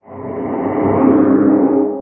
guardian_idle4.ogg